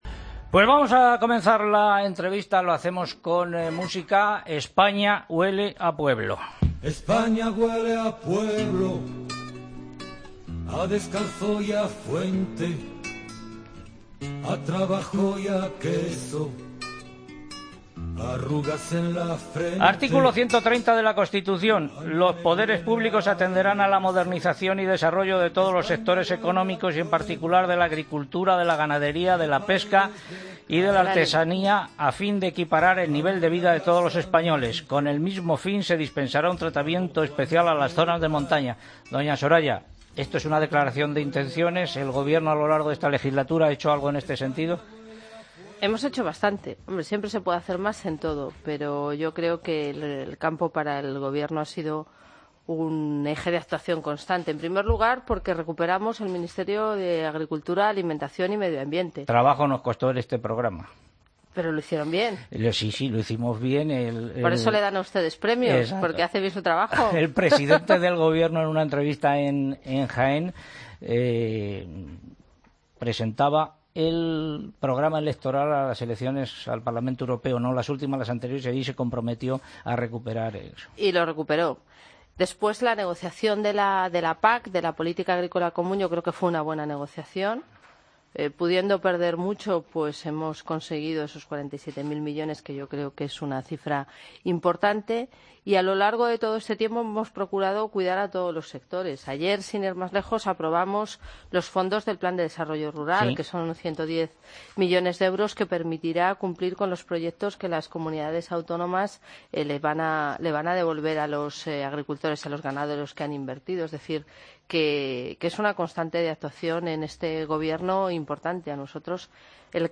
Escucha la entrevista a la vicepresidenta del gobierno